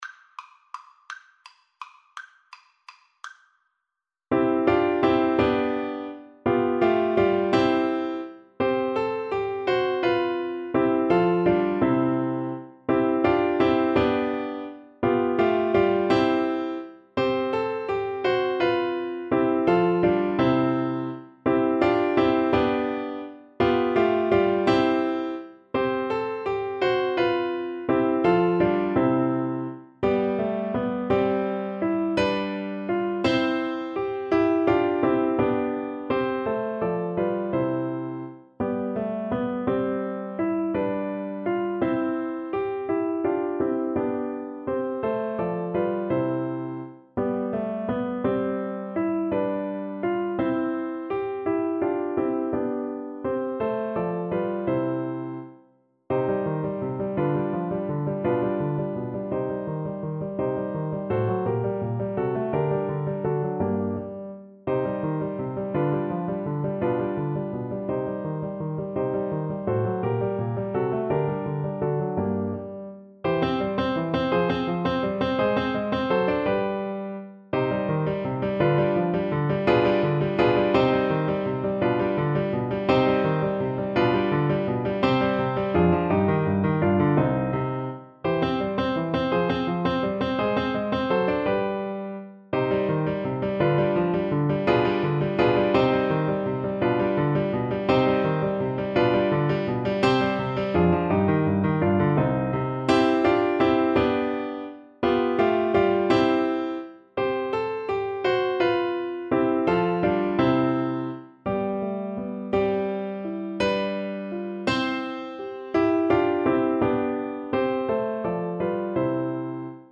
Lustig (Happy) .=56
3/8 (View more 3/8 Music)
Classical (View more Classical Trumpet Music)